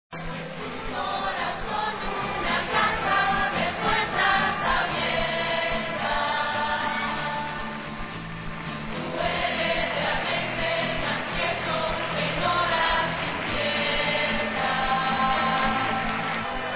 Uroczystość powitalna mająca charakter żywiołowego święta, odbyła się w olbrzymich rozmiarów hangarze, do którego wjechał samolot wiozący Ojca Świętego.
MP3 32 kB -Fragment pieśni "Amigo"